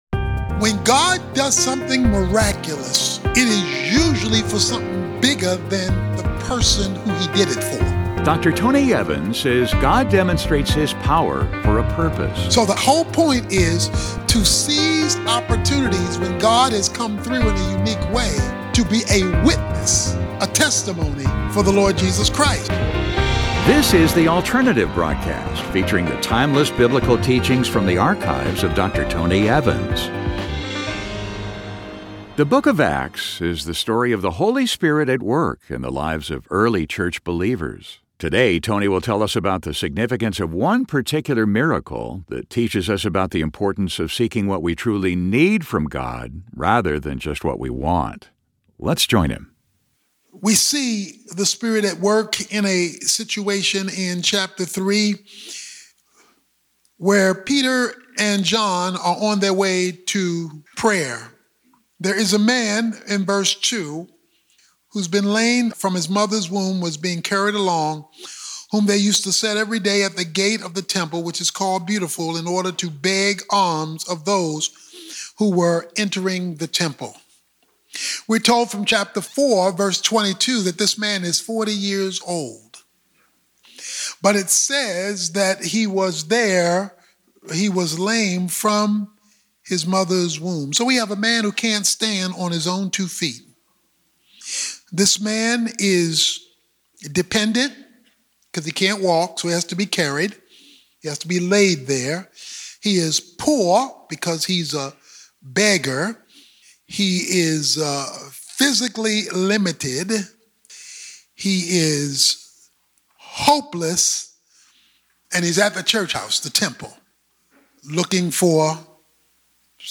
Some miracles go deeper than meets the eye. In this message, Dr. Tony Evans shares how one miracle chronicled in the book of Acts reveals the difference between what we Â want Â from God and what we truly Â need.